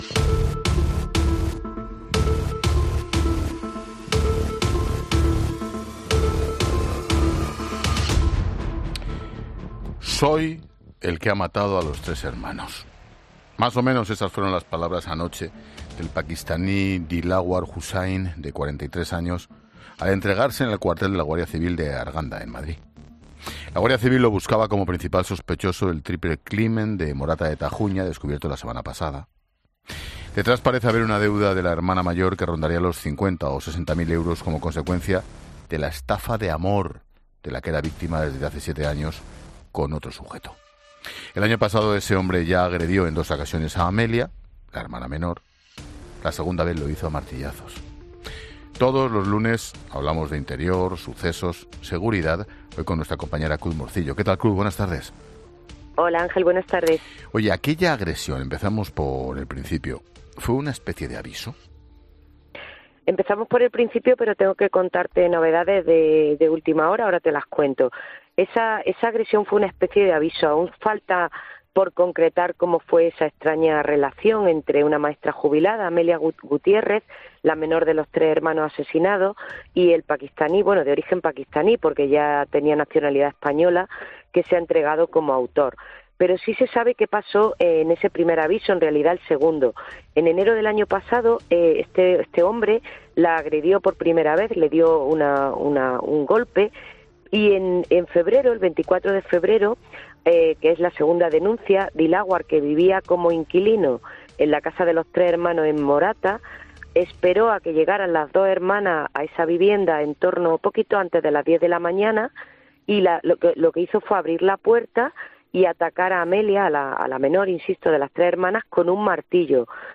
La periodista señala a Expósito las últimas novedades sobre el caso del asesinato de tres hermanos en la localidad madrileña